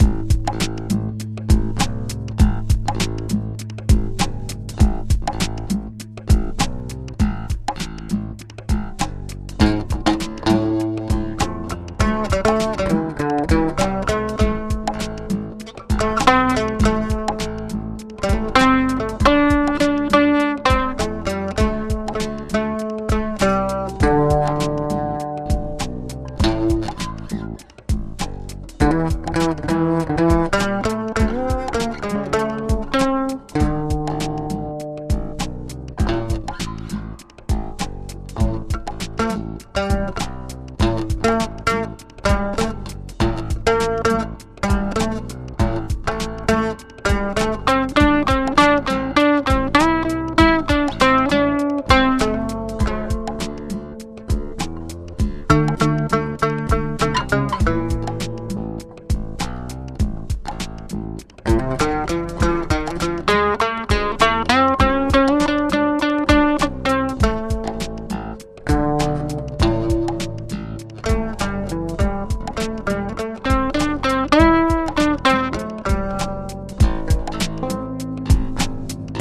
Donc, sur l'insitsement et la demande appuyée de notre cher empereur, voilà un petit sample de cette babasse (enregistré directement via ma table dans la carte son). Soyez indulgents, c'est la première fois que je touche une basse fretless ;)
Le riff de base est enrégistré avec les Lightwave, la ligne de mélodie en simulation magnétique avec piezzo.